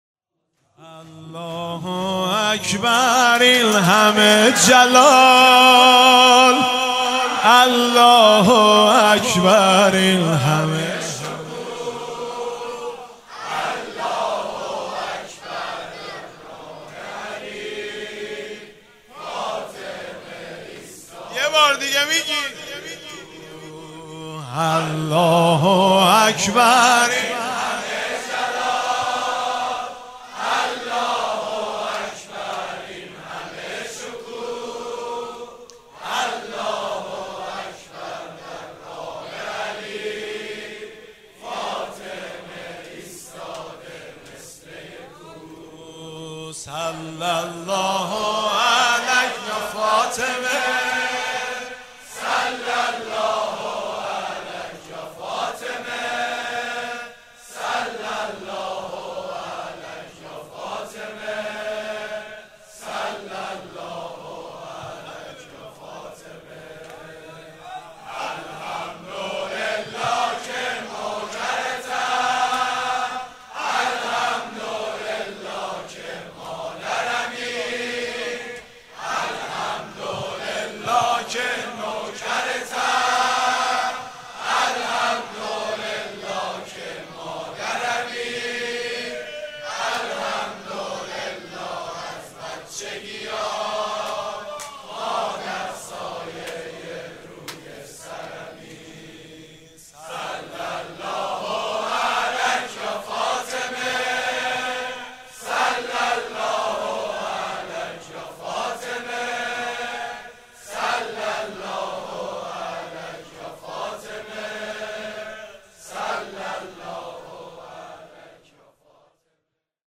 متن مداحی